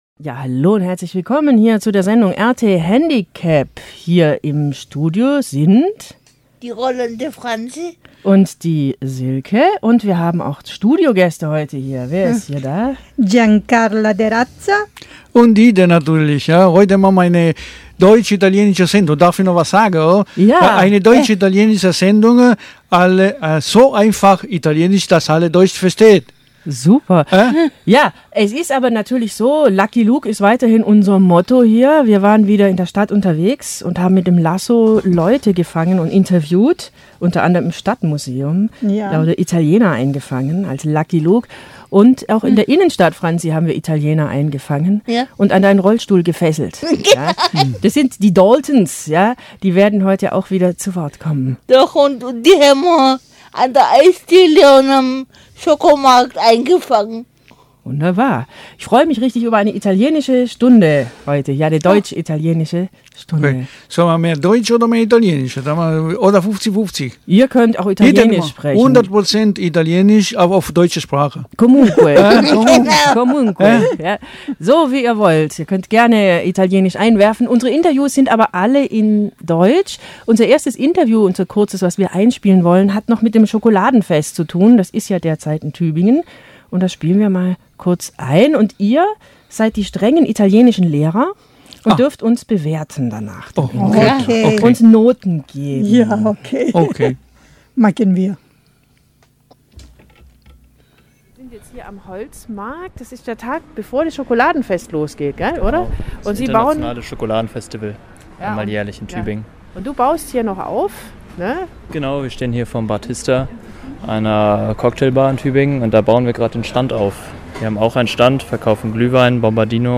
Alle diese Bilder bearbeiten das Thema Baci auf sehr kreative Weise. Vor Ort wurden auch zahlreiche Aufnahmen von der Ausstellungseröffnung gemacht und bei uns in dieser Sendung eingespielt.